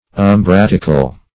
Search Result for " umbratical" : The Collaborative International Dictionary of English v.0.48: Umbratic \Um*brat"ic\ ([u^]m*br[a^]t"[i^]k), Umbratical \Um*brat"ic*al\ ([u^]m*br[a^]t"[i^]*kal), a. [L. umbraticus, from umbra shade.